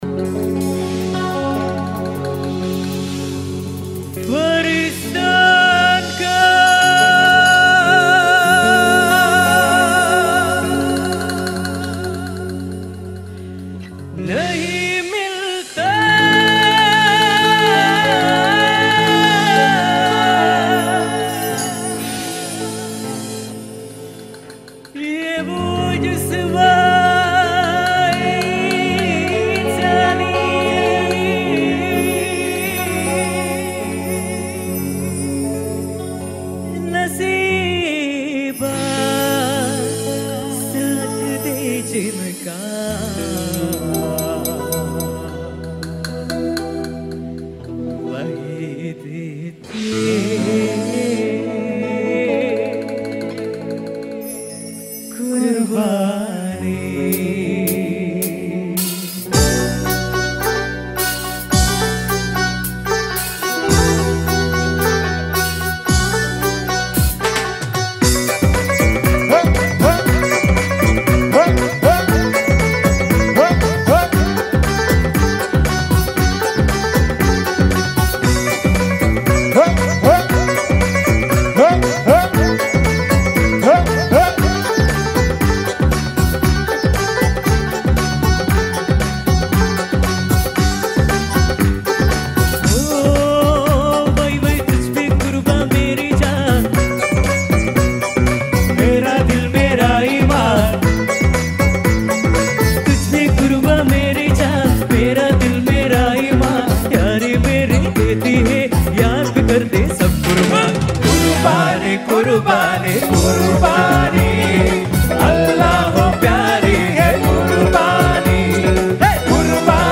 Srilanka No.1 Live Show Download Site...